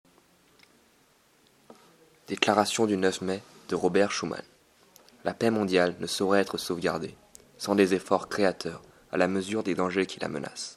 Lecture d'un passage de la célèbre déclaration de Schuman du 9 mai (Ventana nueva)